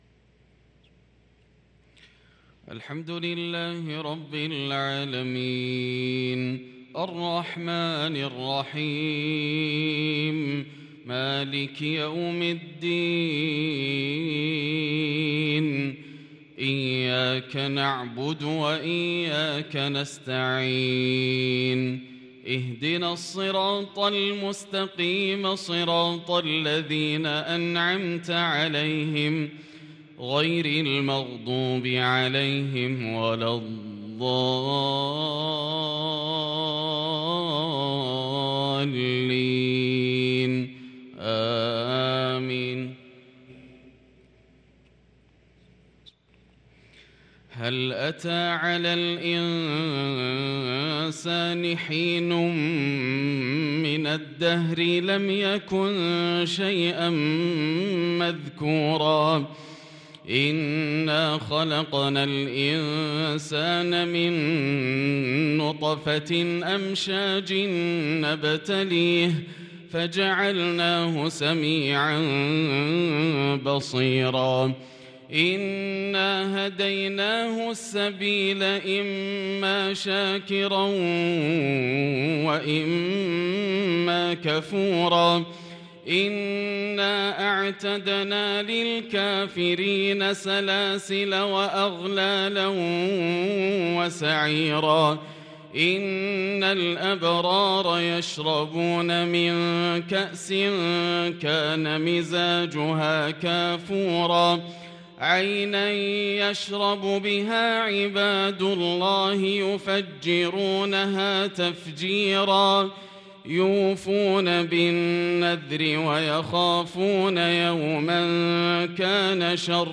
صلاة الفجر للقارئ ياسر الدوسري 22 شوال 1443 هـ